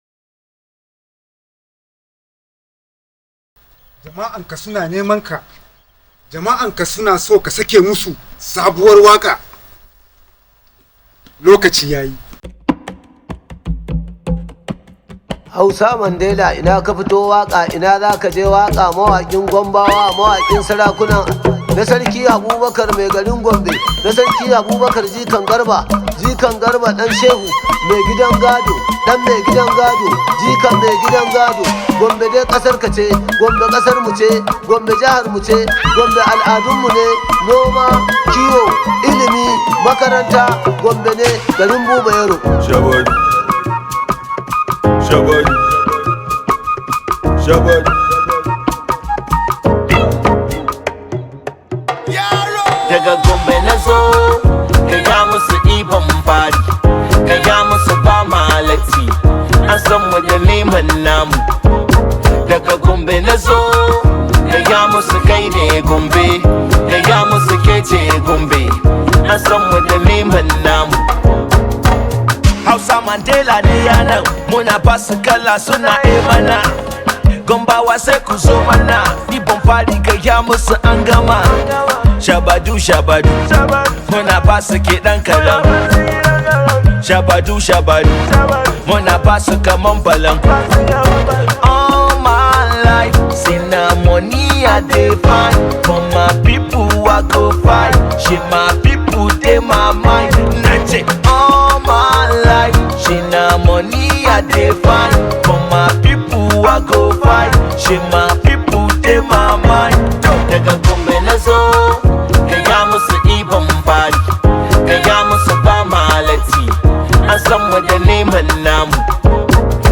hausa music track
Arewa rooted song